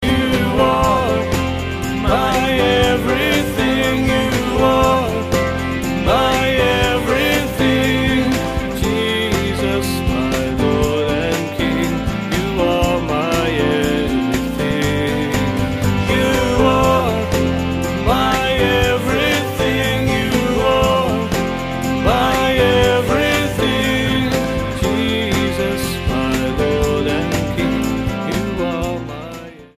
STYLE: Pop
just drums, keyboards and guitar
rich baritone voice